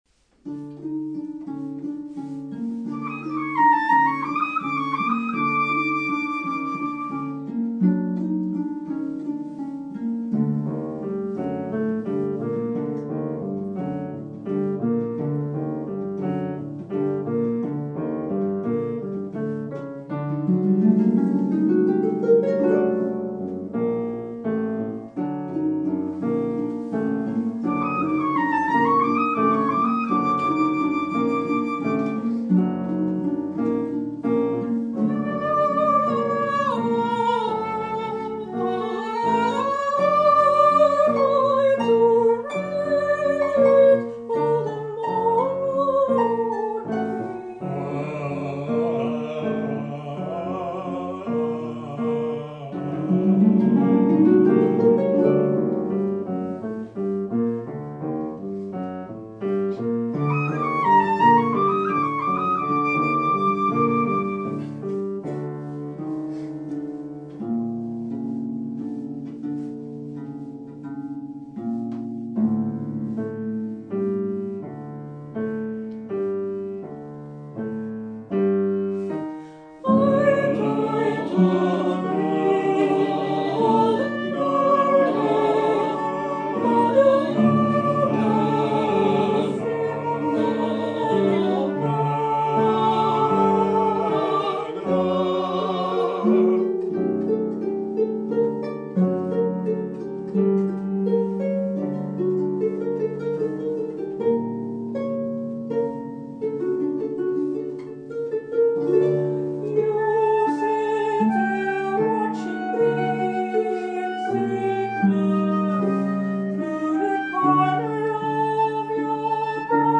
set on music for different combination of voices and instruments.
Gardener: soprano, alto, bariton, flute, harp, piano, cello; 7:13 minutes